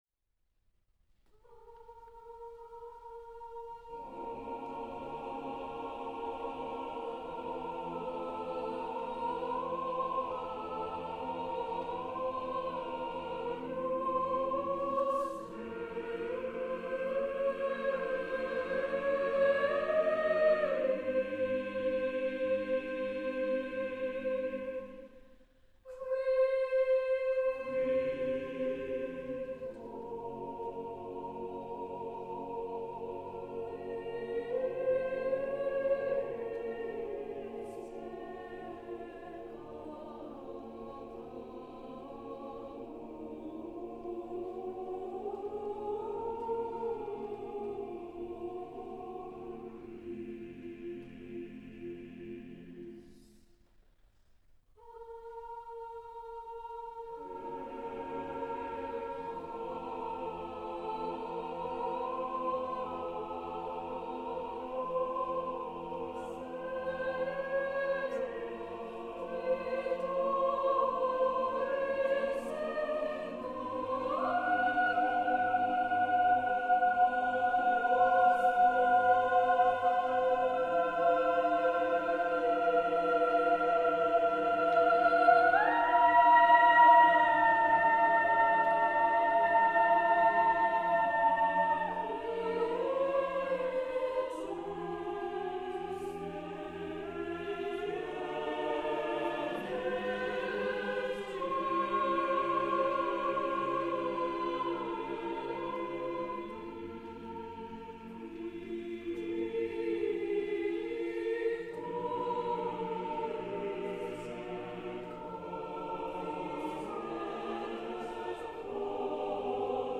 川口リリア音楽ホール